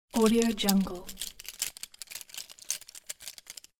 دانلود افکت صوتی خش‌خش پلاستیک1
Plastic Sticks Rustling 1 royalty free audio track is a great option for any project that requires domestic sounds and other aspects such as a sfx, sound and toy.
Sample rate 16-Bit Stereo, 44.1 kHz
Looped No